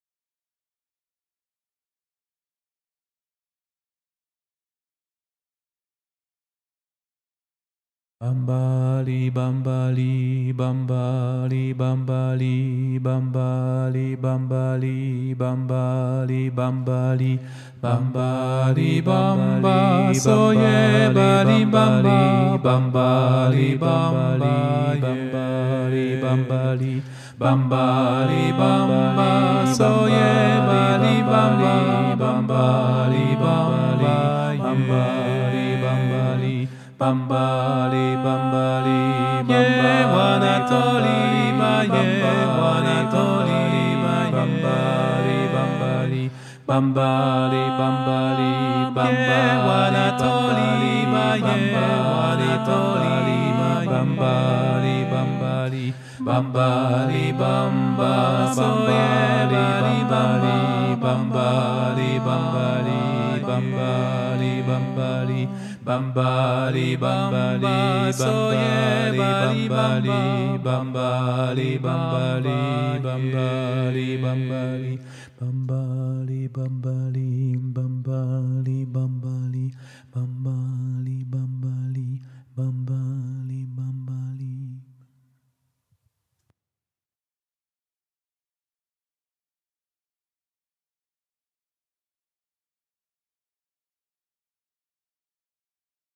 - Chant traditionnel du Sénégal
MP3 versions chantées